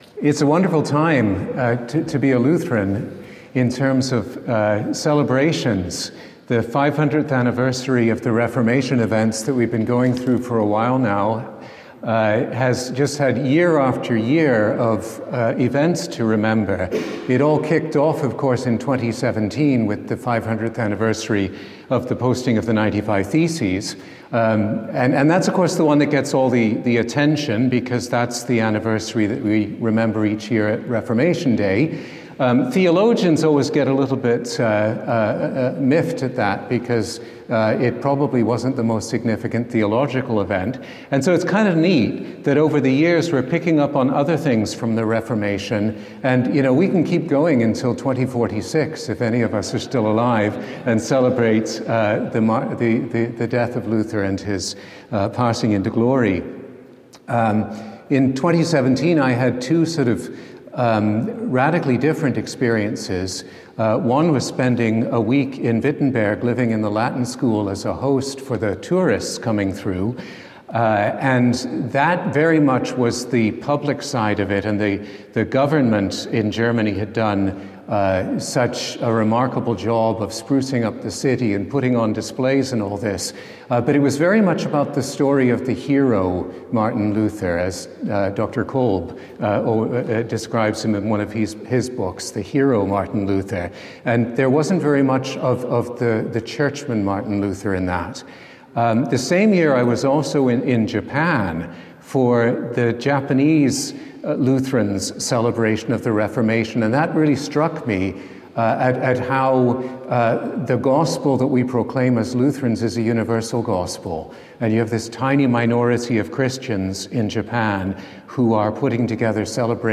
A lecture
to the inaugural Confessio conference at Concordia University, St. Paul, MN